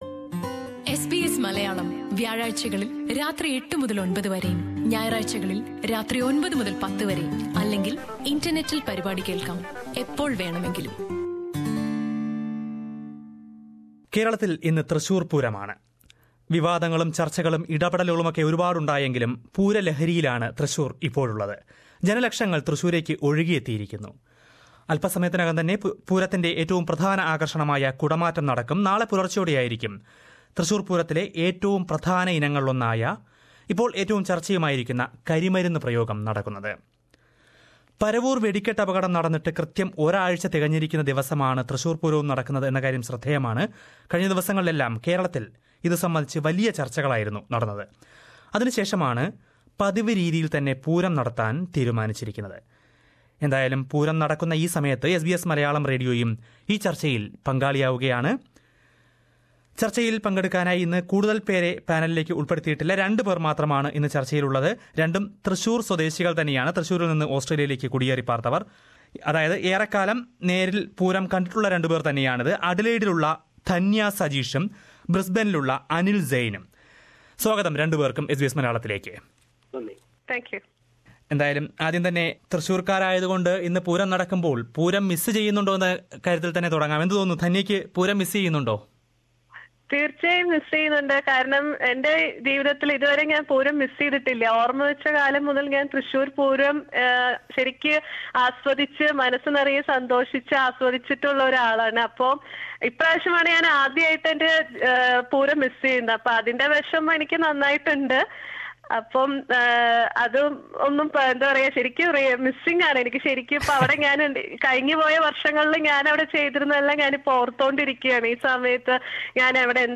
പൂരം ദിനത്തിൽ ഓസ്ട്രേലിയൻ ദേശീയ റേഡിയോ ആയ എസ് ബി എസ് മലയാളവും ഇതേ വിഷയം ചർച്ചയ്ക്കായെടുത്തു. തൃശൂരിൽ നിന്ന് ഓസ്ട്രേലിയയിലേക്ക് കുടിയേറിയെത്തിയ രണ്ടു പേരാണ്, പൂരം നേരിൽ കണ്ട ഓർമ്മകളുമായി ഈ ചർച്ചയിൽ പങ്കെടുത്തത്.